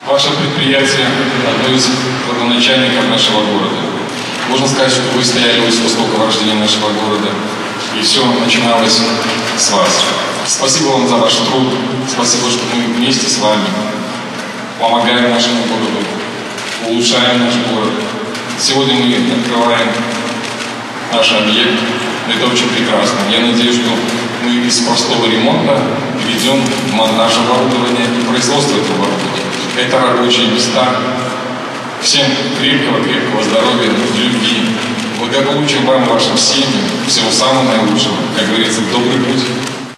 Заместитель председателя Барановичского горисполкома Александр Козека поздравил всех присутствующих и поблагодарил коллектив за продуктивный труд.